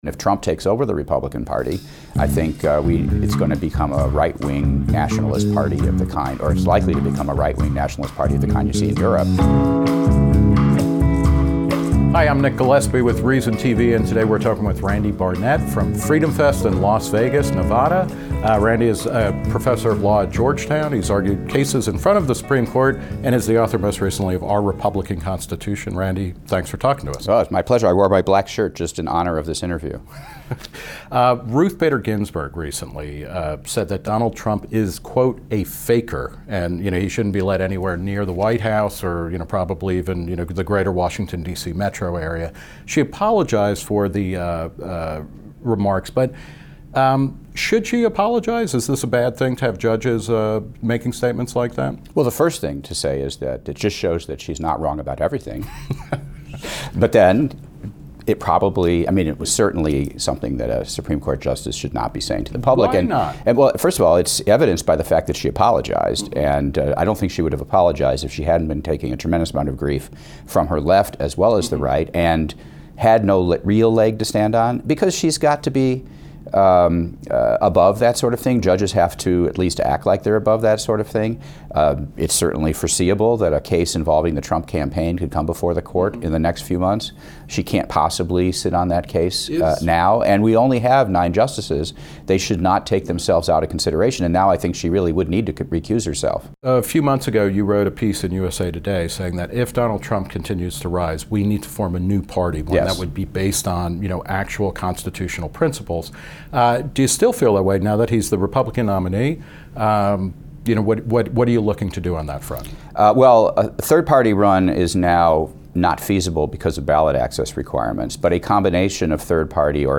Reason TV's Nick Gillespie sat down with Barnett to discuss the GOP's future, what the 2016 election portends, and why Supreme Court Justice Ruth Bader Ginsburg was right to apologize for her critical remarks about Trump.